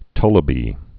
(tŭlə-bē)